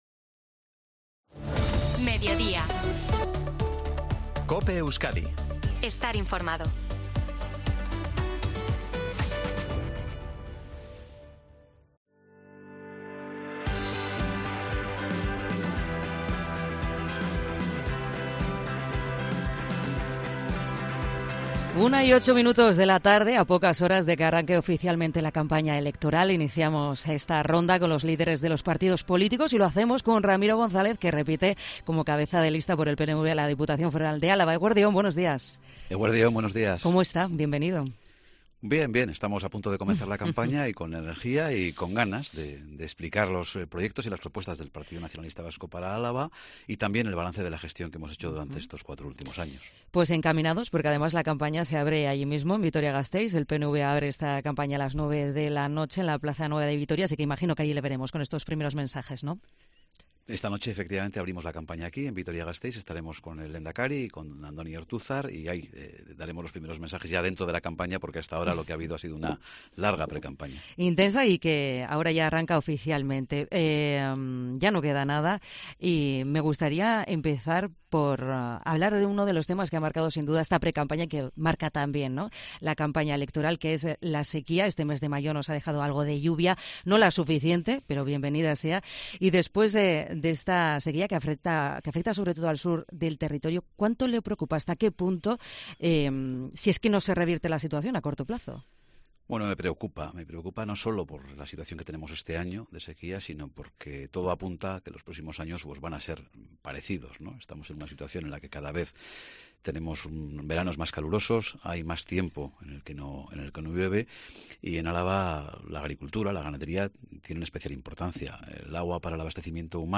Entrevista a Ramiro González